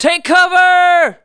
SCI-GRENADE3.mp3